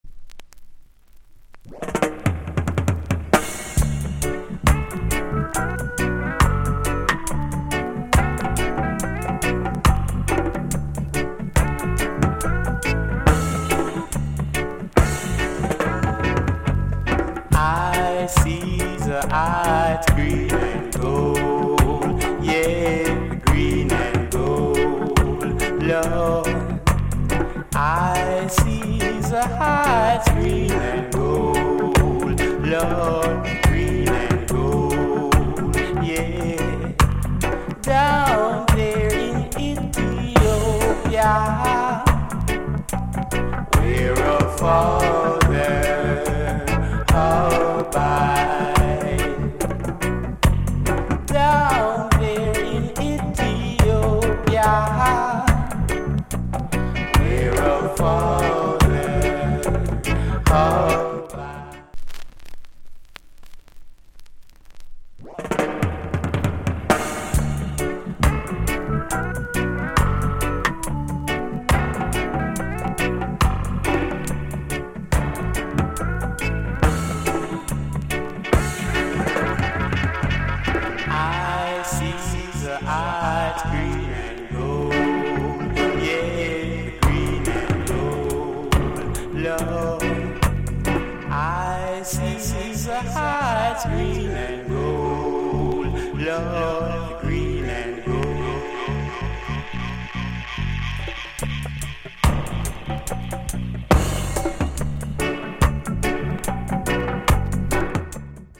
Male Vocal Group Vocal Condition EX